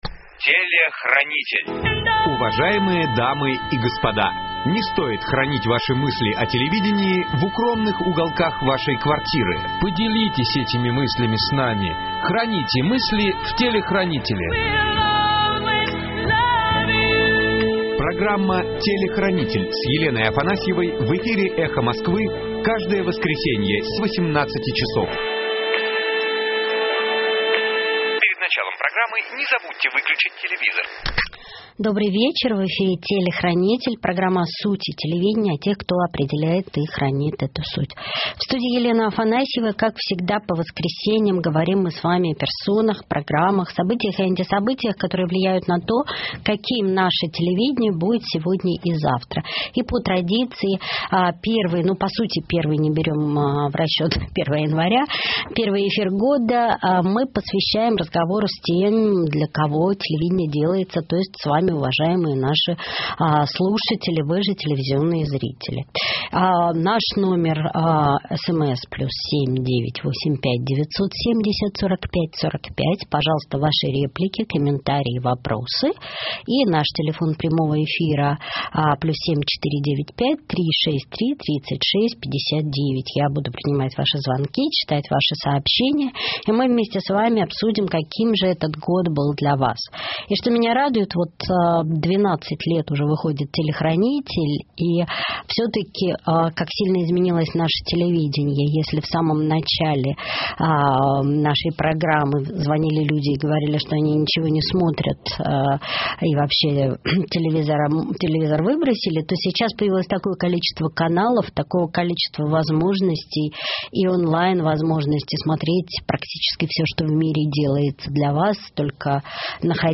Конференция со слушателями - Телехранитель - 2017-01-08